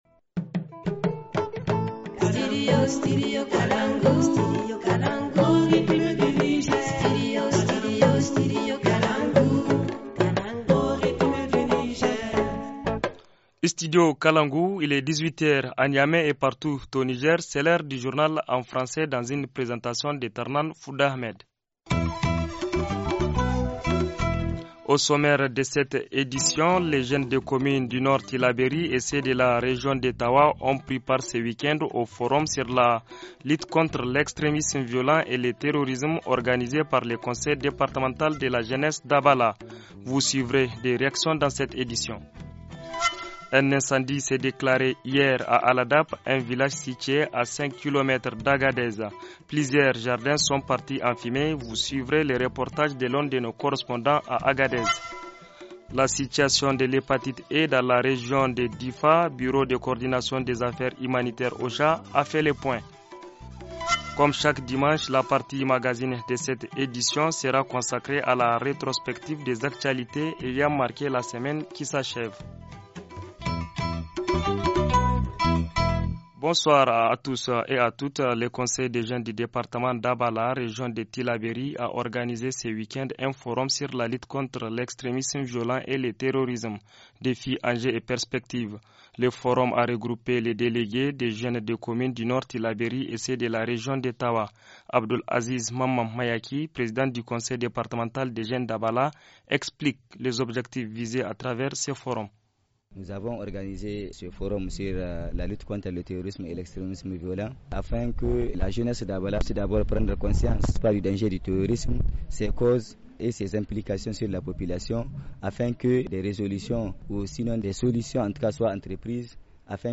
Plusieurs jardins sont partis en fumée, vous suivrez le reportage de l’un de nos correspondants à Agadez